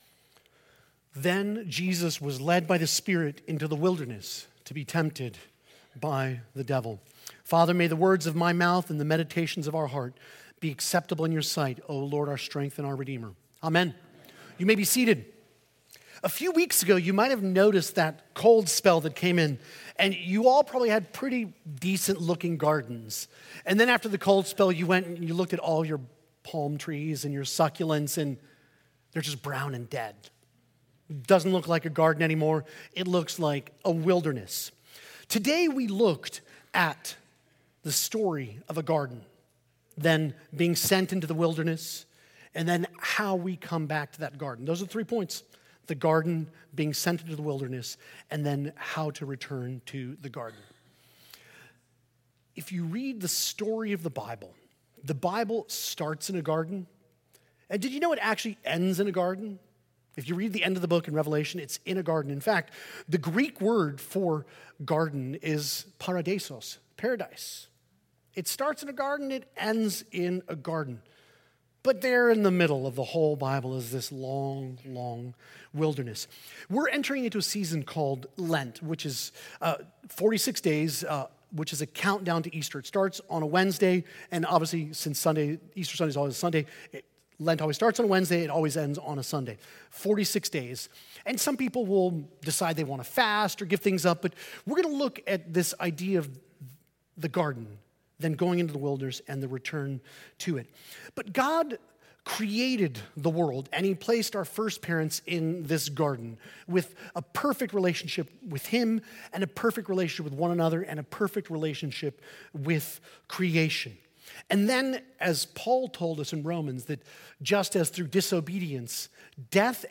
The sermon traces the biblical story arc from the Garden of Eden, through the wilderness of sin, to our return to the garden made possible through Christ. It highlights that Scripture both begins and ends in a garden—Paradise lost and Paradise restored.